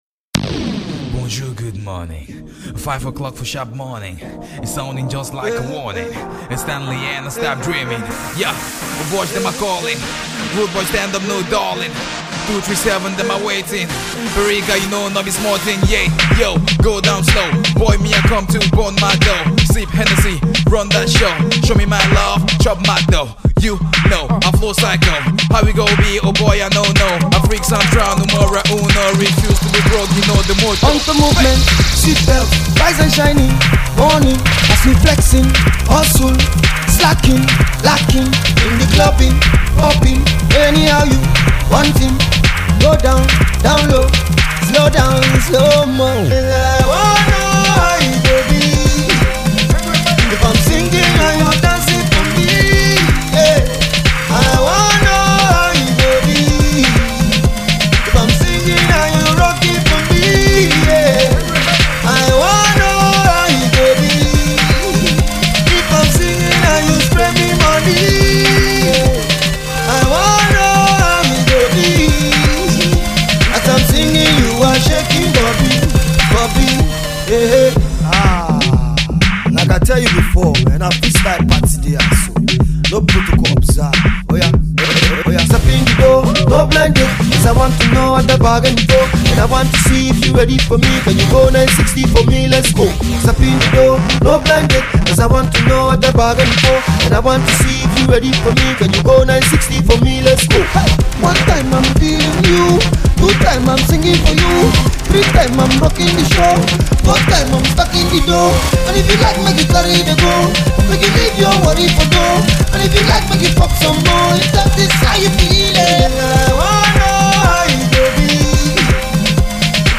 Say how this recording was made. remastered version